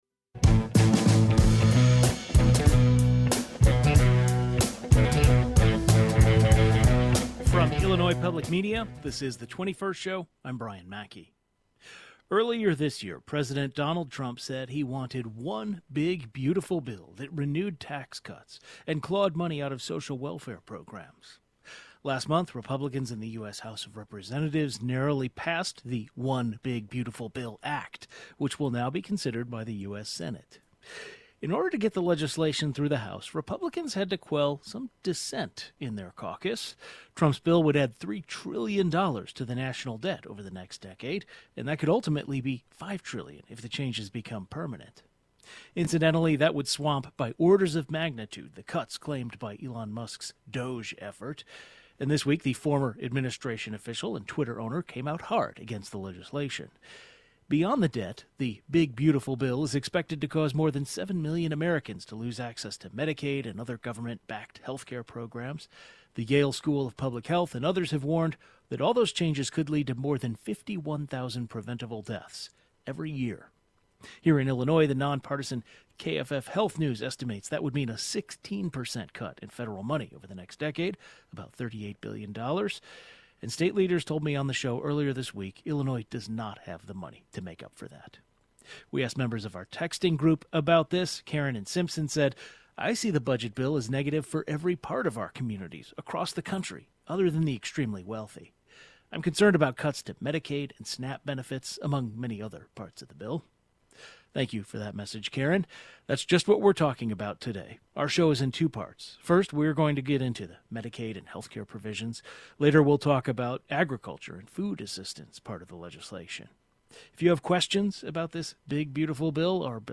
Two public policy experts offer their analysis.